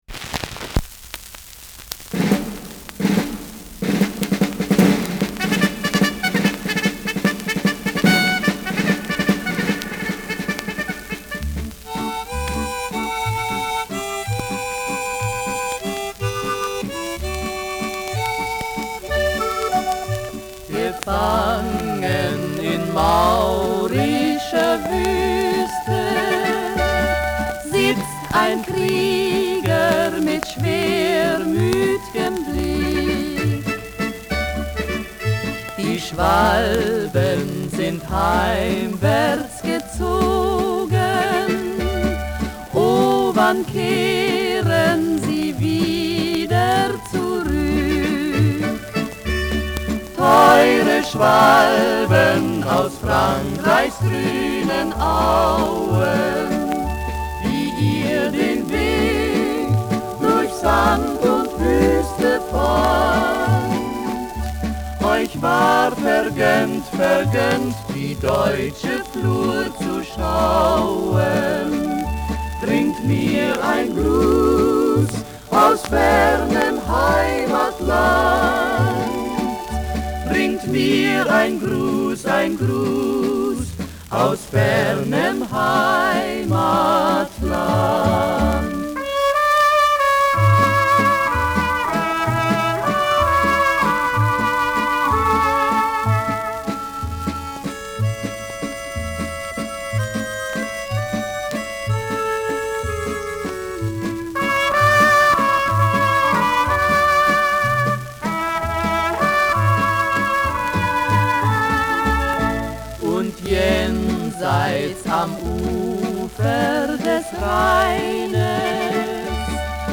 Schellackplatte
Leicht abgespielt : Vereinzelt leichtes Knacken